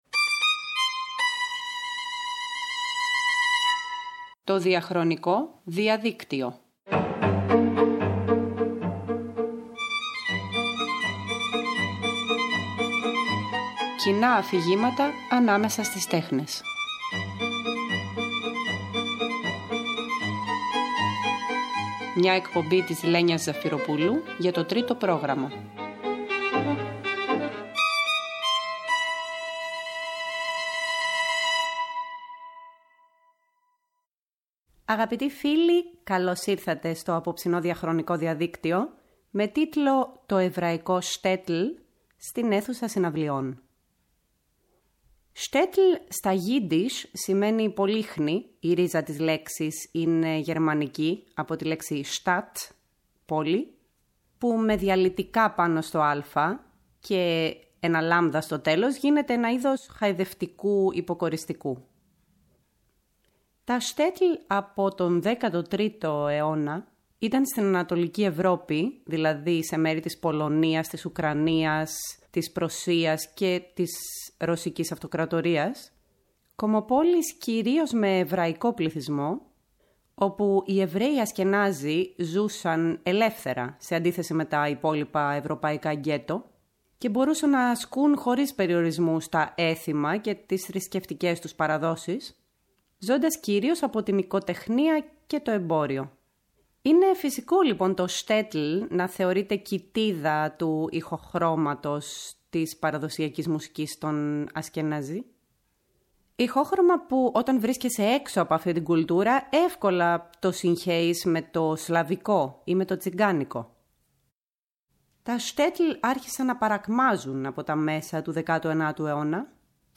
Στο αφιέρωμα αυτό θα ακούσουμε μαζί τέτοια έργα, όπως και μουσική ζώντων συνθετών που εμπνέονται από τη μουσική παράδοση της εβραϊκής διασποράς.